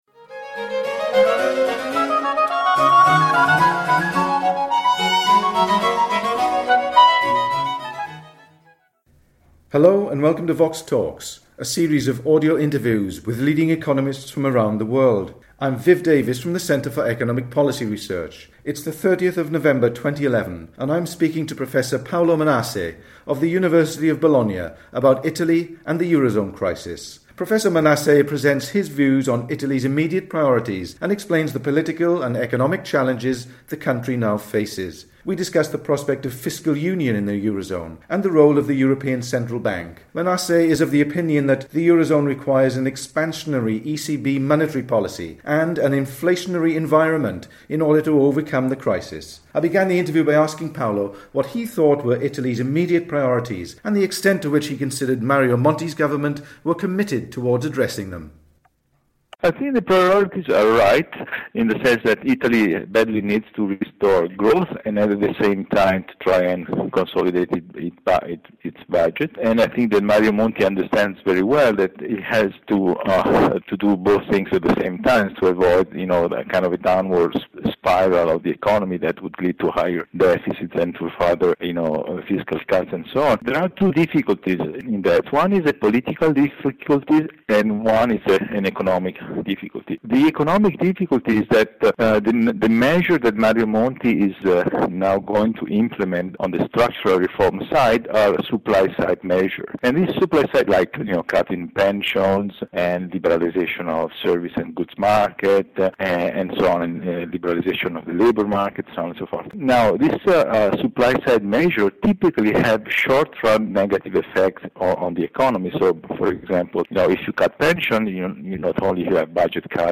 The interview was recorded on 30 November 2011.